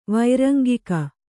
♪ vairangika